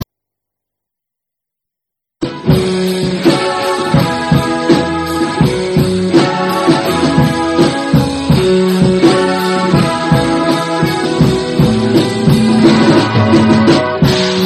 baroque Christmas tunes